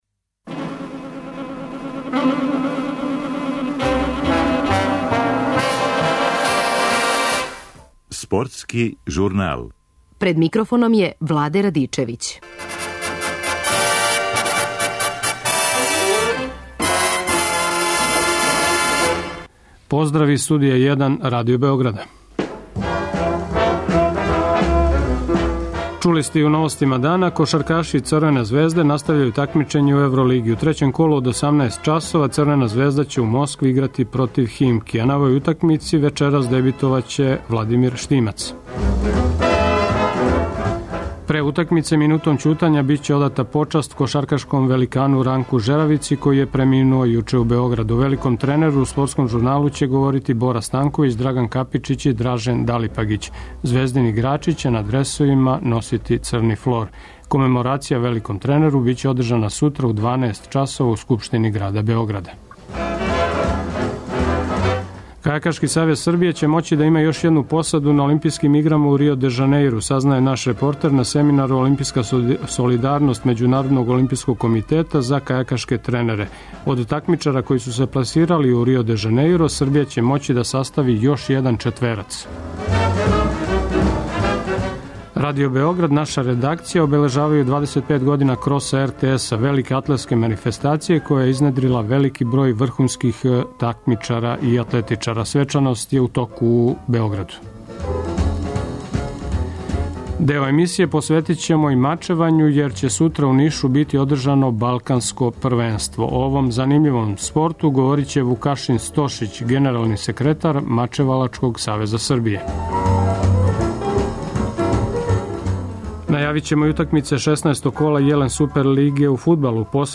О великом тренеру у емисији ће говорити Бора Станковић, Драган Капичић и Дражен Далипагић.